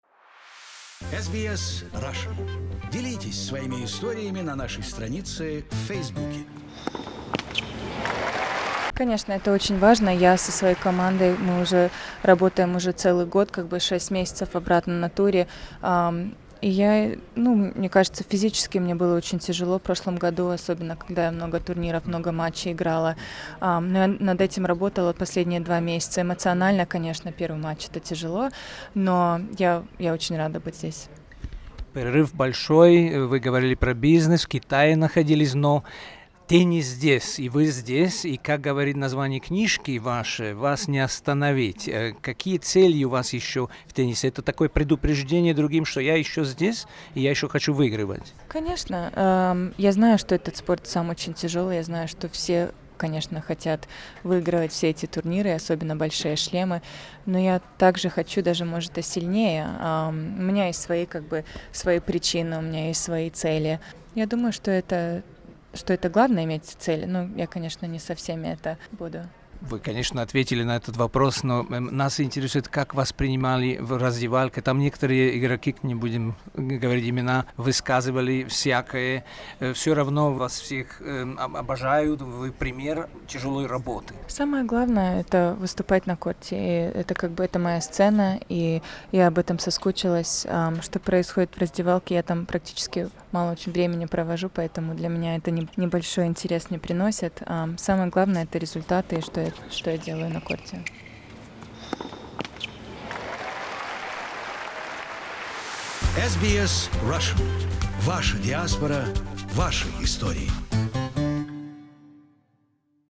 On the second day of the Australian Open Maria Sharapova has won an easy victory over German Tatjana Maria in two sets 6:1, 6:4. After the game she had a minute to chat, and we asked her how important is the teamwork for an elite spots person and about her feelings after a considerable break